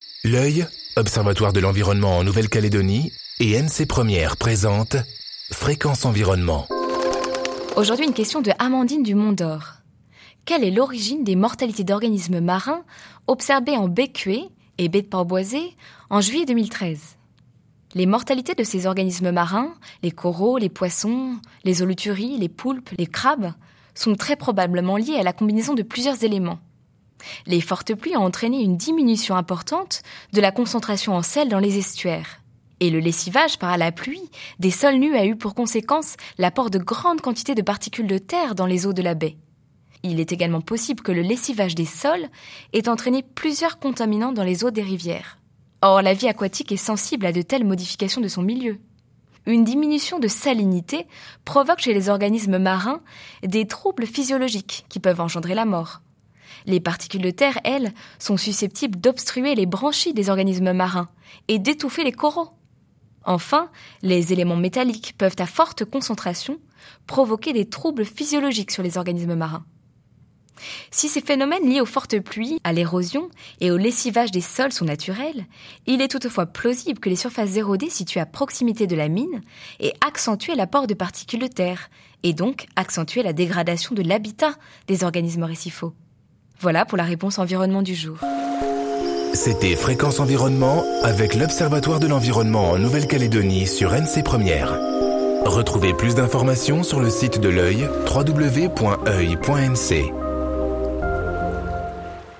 diffusée en janvier 2014 sur NC 1ère